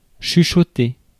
Ääntäminen
US : IPA : [wɪs.pə(r)]